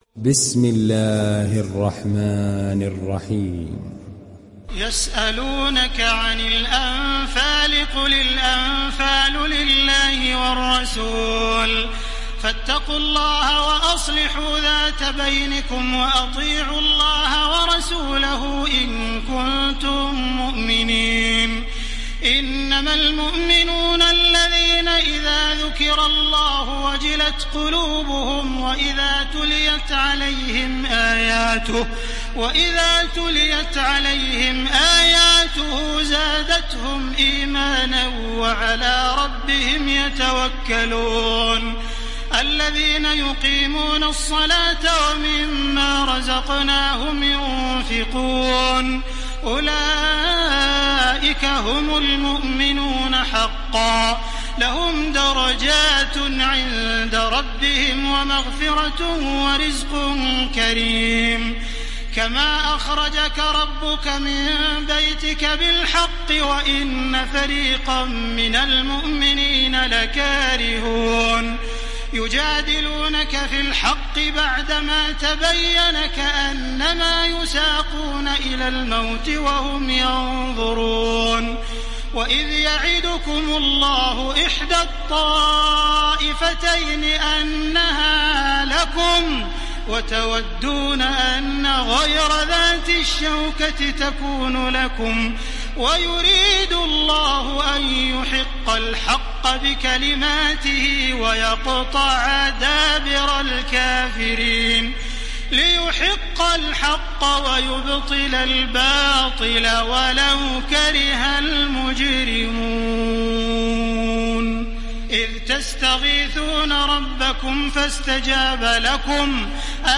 Download Surat Al Anfal Taraweeh Makkah 1430